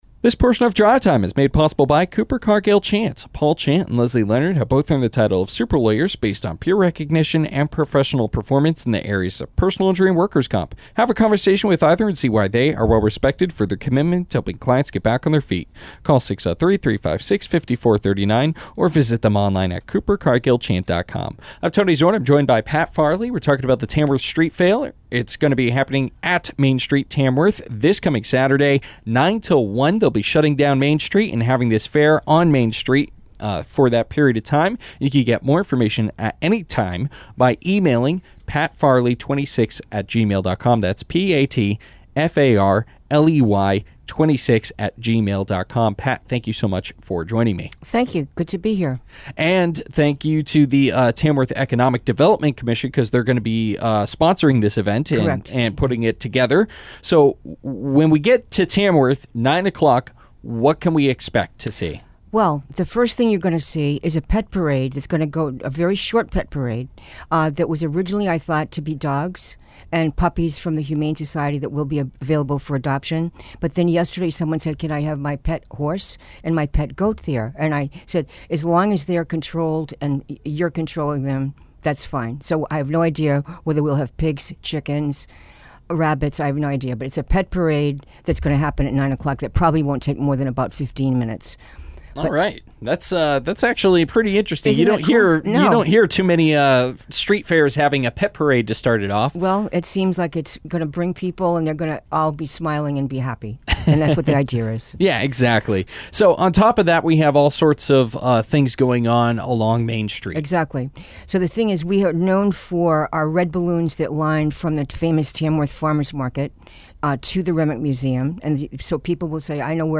Drive Time Interview: Tamworth Street Fair
Drive Time Interviews are a specialty program on week days at 5pm where local not for profit organizations get a chance to talk about an upcoming event on air.